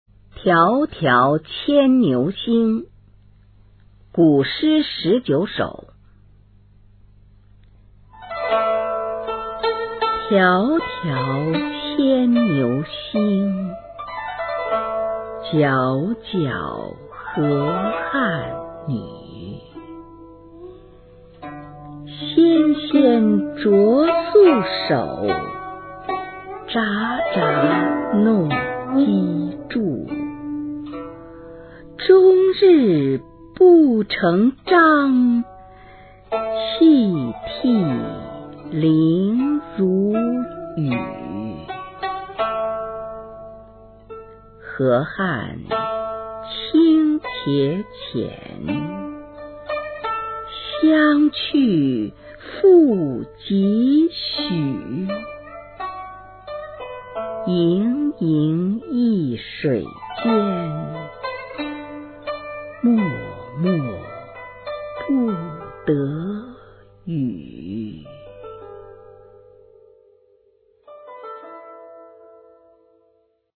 首页 视听 语文教材文言诗文翻译与朗诵 初中语文七年级下册
《迢迢牵牛星》原文译文和鉴赏（含Mp3朗读）　/ 佚名